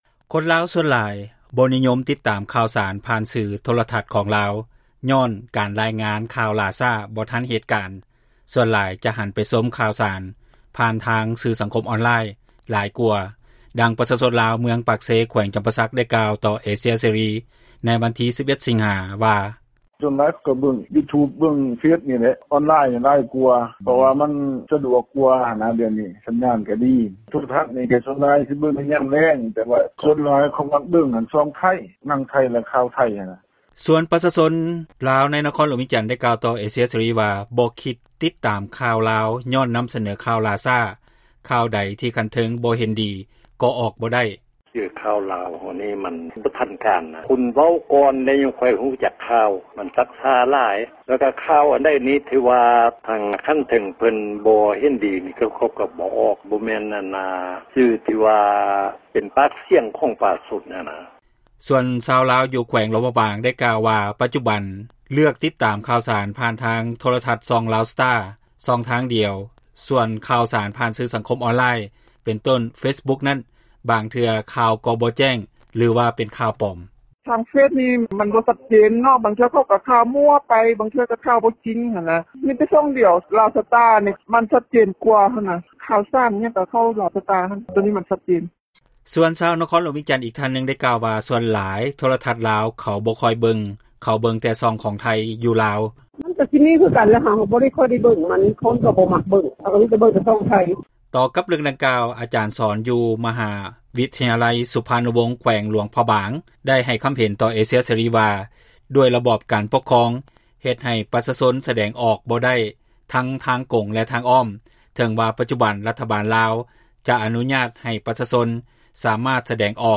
ຄົນລາວສ່ວນຫຼາຍ ບໍ່ນິຍົມຕິດຕາມຂ່າວສານ ຜ່ານທາງໂທຣະທັສຂອງລາວ ຍ້ອນການຣາຍງານຂ່າວ ຫຼ້າຊ້າບໍ່ທັນເຫດການ, ສ່ວນຫຼາຍ ຄົນຈະຫັນໄປ ຊົມຂ່າວສານຜ່ານ ສື່ສັງຄົມ ອອນລາຍ ຫຼາຍກວ່າ, ດັ່ງປະຊາຊົນລາວ ເມືອງປາກເຊ ແຂວງຈຳປາສັກ ໄດ້ກ່າວຕໍ່ ເອເຊັຽ ເສຣີ ໃນວັນທີ 11 ສິງຫາ 2020 ວ່າ: